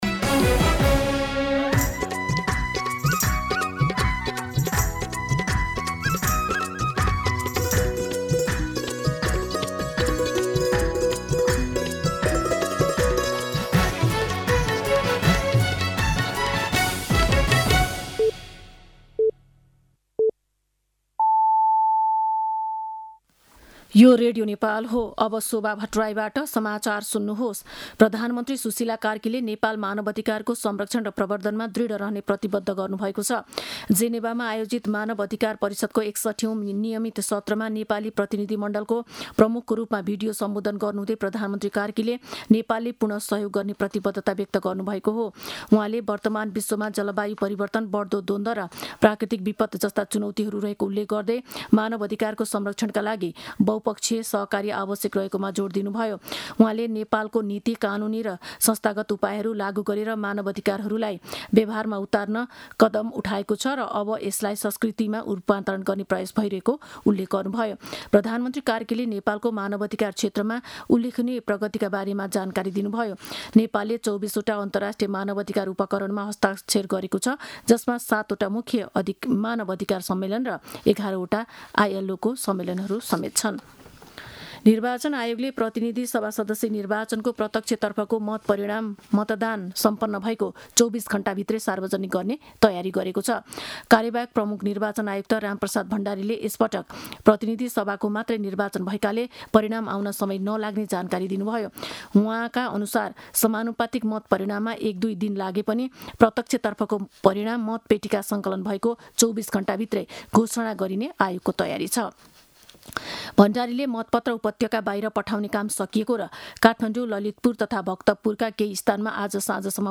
मध्यान्ह १२ बजेको नेपाली समाचार : १२ फागुन , २०८२